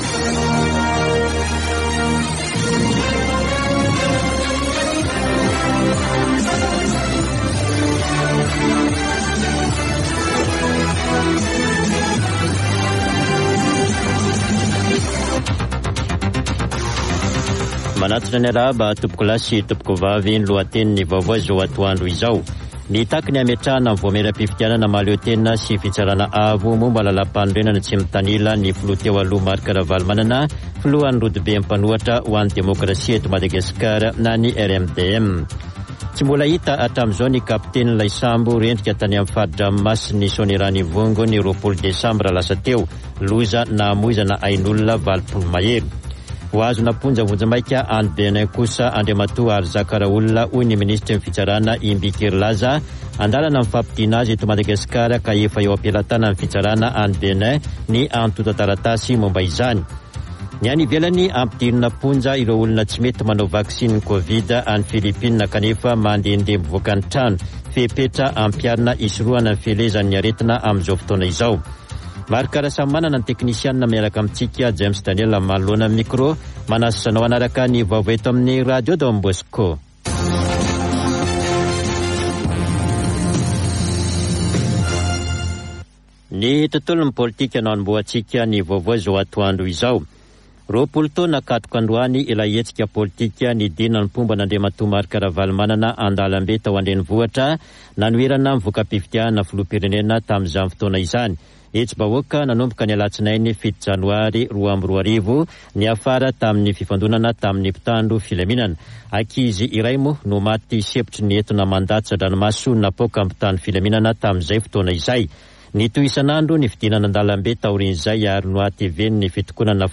[Vaovao antoandro] Zoma 7 janoary 2022